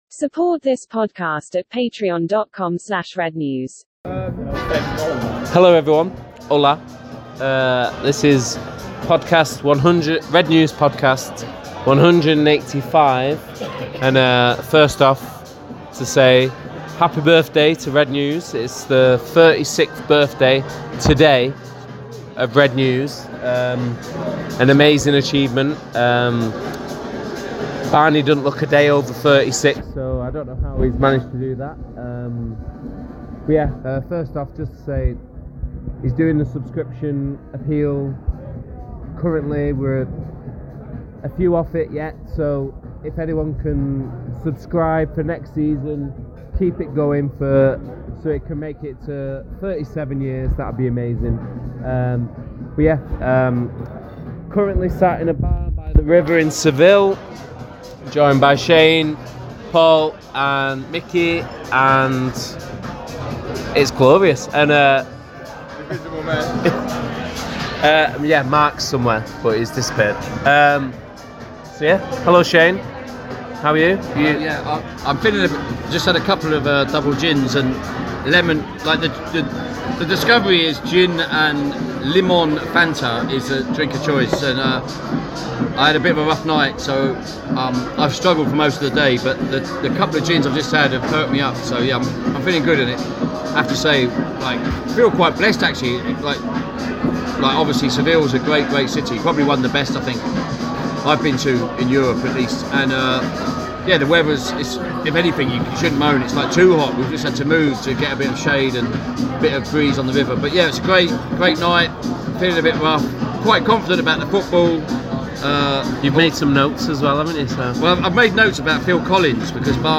Pre-match giddiness replaced by post match rude awakenings - from Seville as Utd go out of the Europa League with a dismal performance.